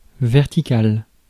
Ääntäminen
Ääntäminen France: IPA: [vɛʁ.ti.kal] Haettu sana löytyi näillä lähdekielillä: ranska Käännös Ääninäyte Adjektiivit 1. vertical US 2. upright US 3. plumb US 4. sheer US Suku: m .